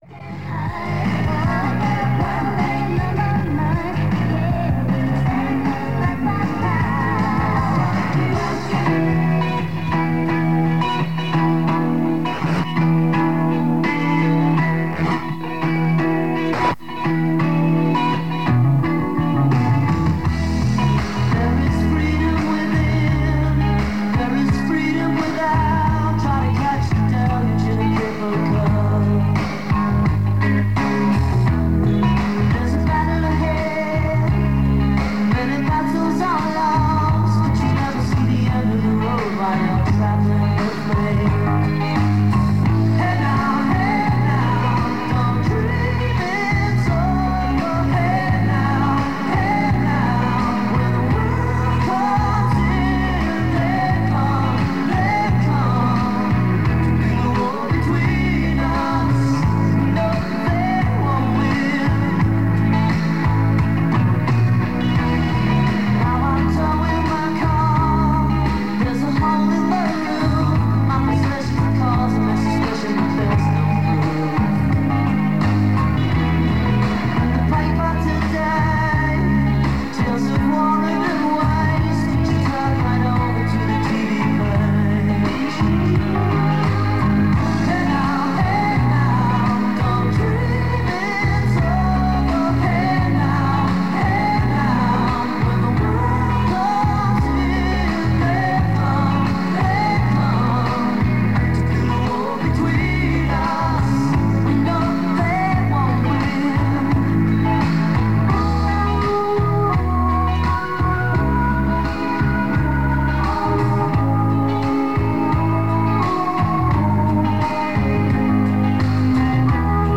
There are few adverts as would be expected for the time of night but a promo for the BIg Kahuna competition is aired.
Audio is generally good but there is some wobble at the start due to cassette degradation.